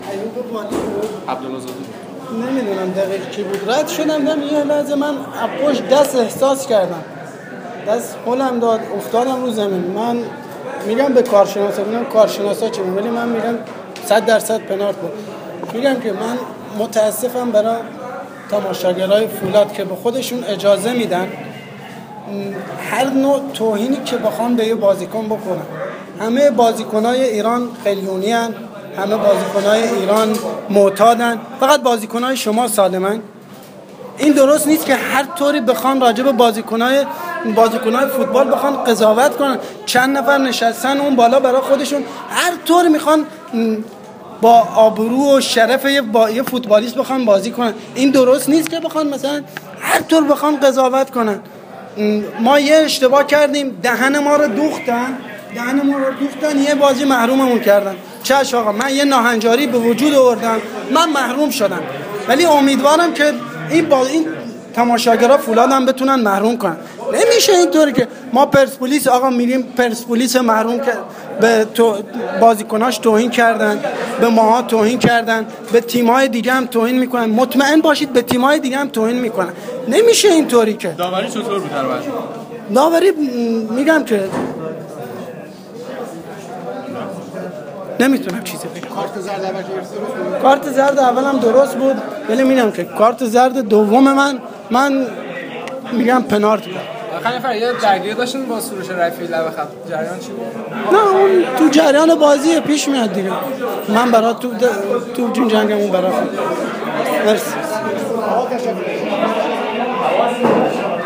(بهمراه فایل صوتی مصاحبه)